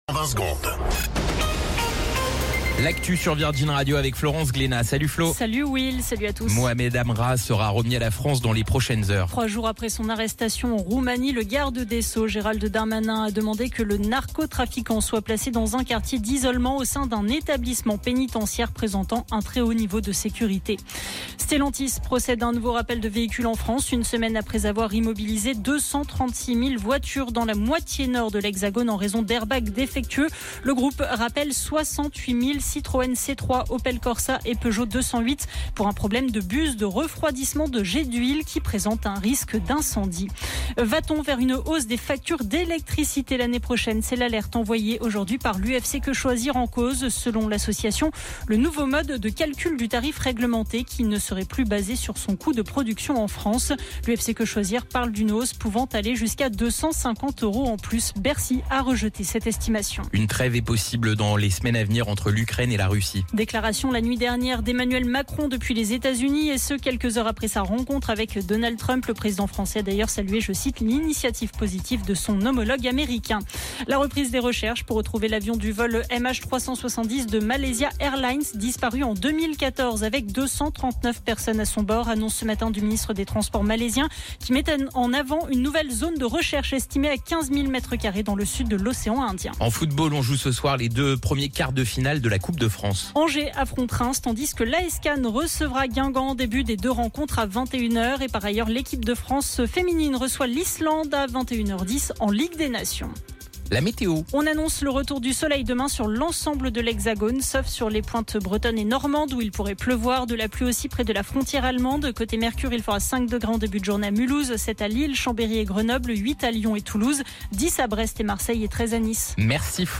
Flash Info National 25 Février 2025 Du 25/02/2025 à 17h10 .